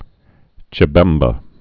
(chĭ-bĕmbə)